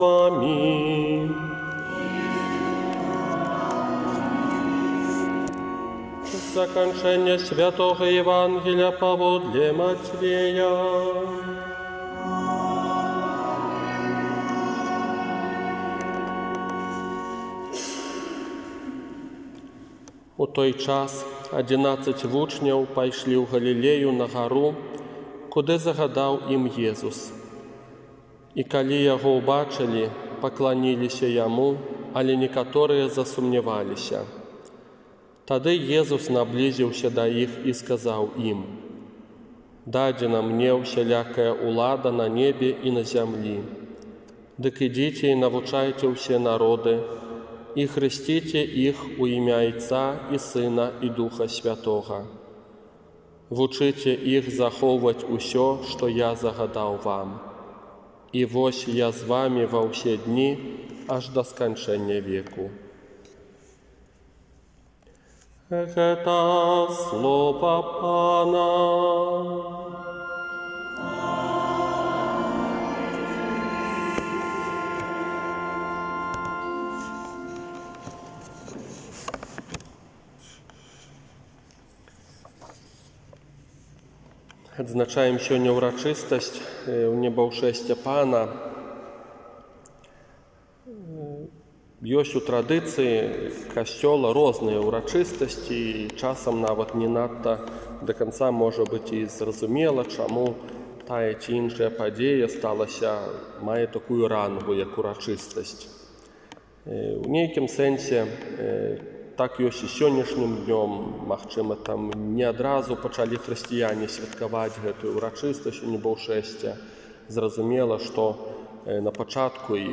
Казанне на Ўрачыстасць Унебаўшэсця Пана 31 мая 2020 года